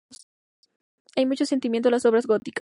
sen‧ti‧mien‧to
/sentiˈmjento/